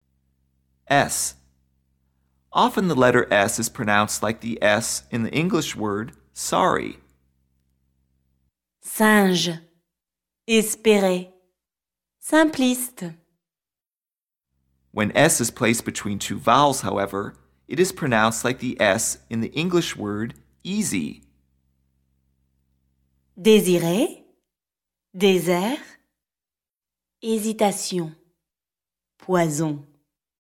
s – Often the letter “s” is pronounced like the “s” in the English word “sorry.” singe, espérer, simpliste